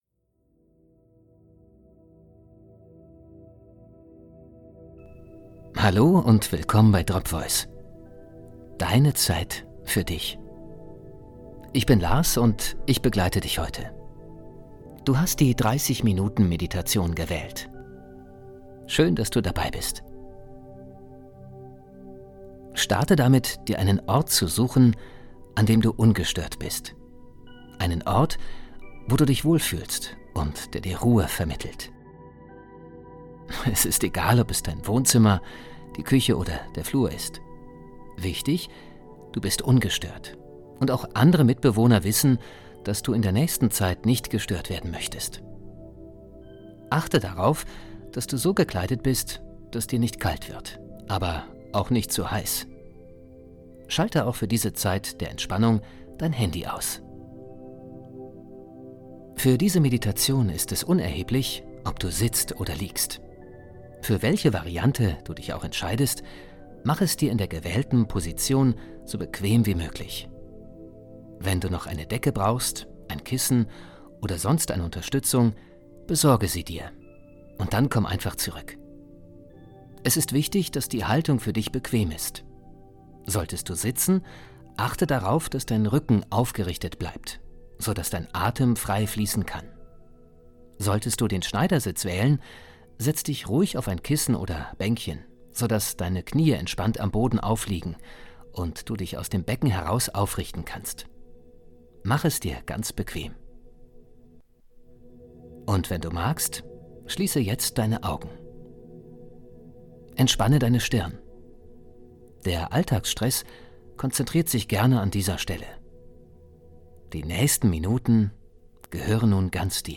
Atemmeditation (30min) -mit Hintergrundmusik
Beschreibung vor 1 Jahr Einfache Atemmeditation für Anfänger - diese Folge aber mit Musik, wer's mag.
DropVoice_30min_MP3b_mit_Hintergrundmusik.mp3